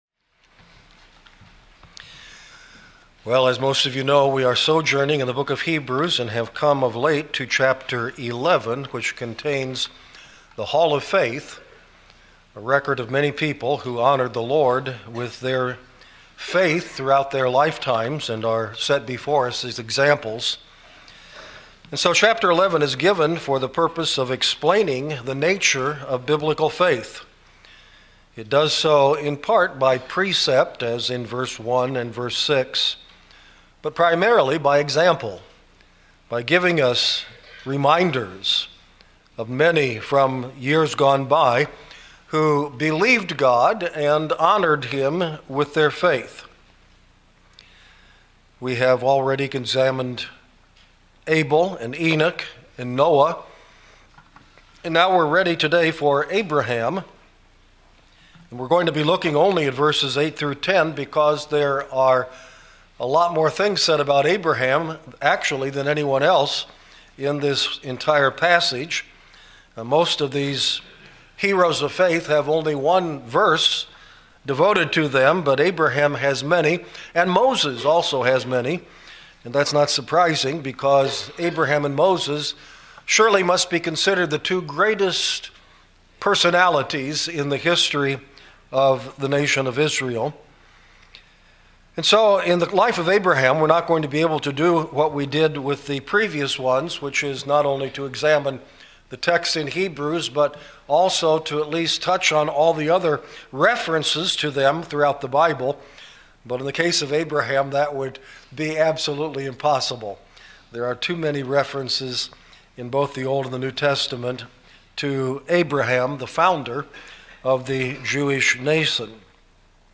In this message we gain insights into the nature of Biblical faith from the life of Abraham.